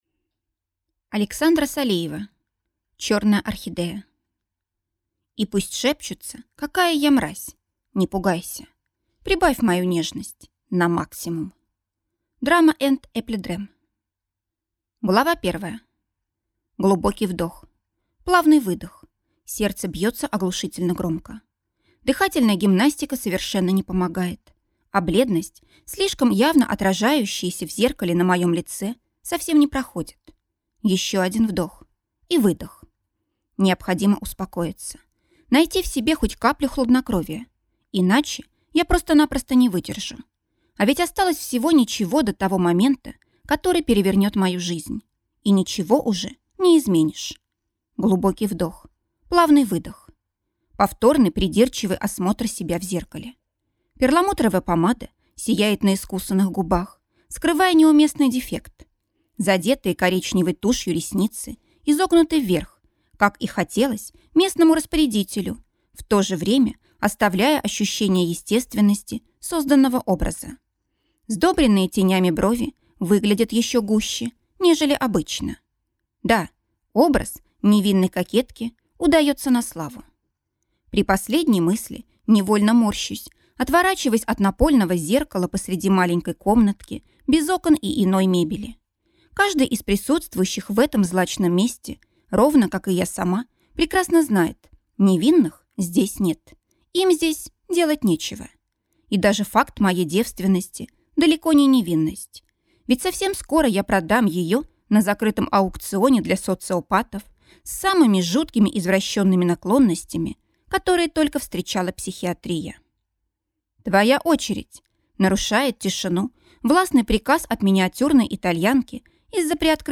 Аудиокнига Чёрная орхидея | Библиотека аудиокниг